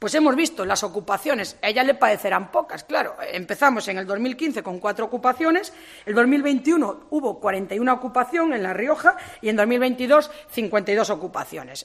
Vázquez ha realizado estas declaraciones, en Logroño, en un encuentro con los medios de comunicación en el que ha estado acompañada del diputado nacional del PP de La Rioja